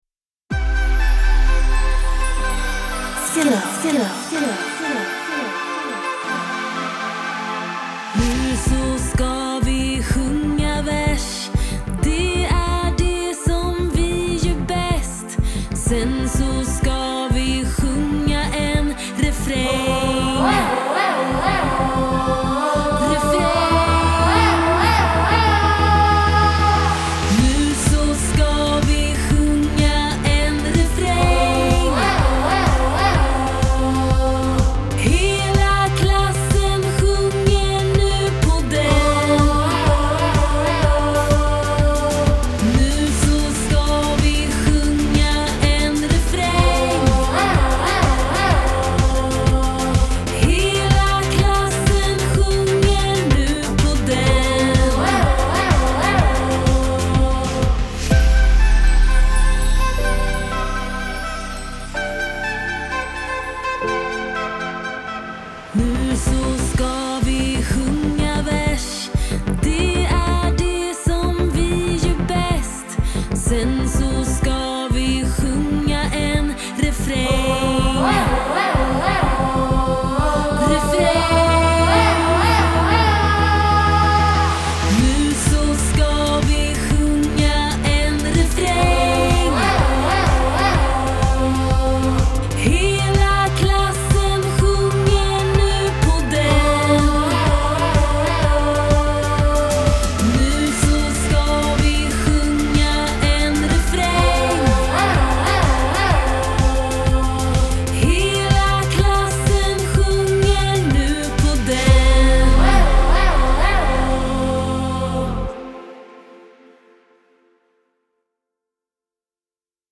Melodi med vers och refräng